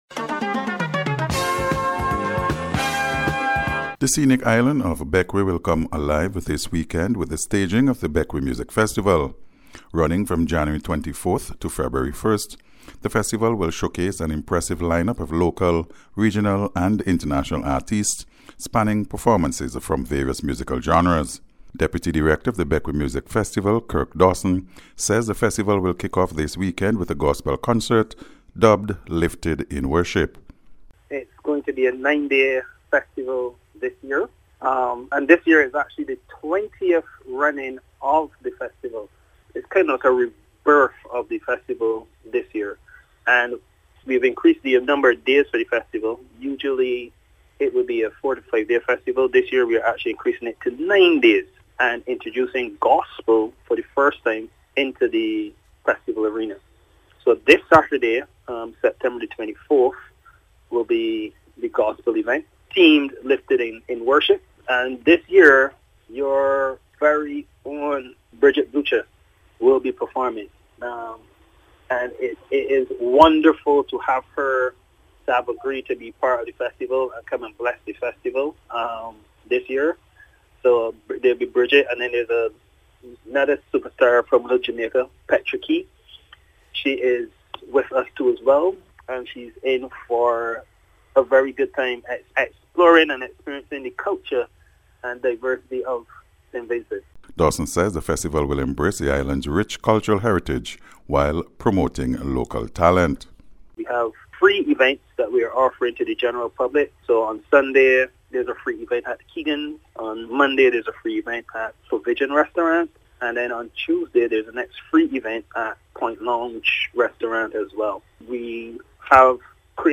NBC’s Special Report- Thursday 22nd January,2025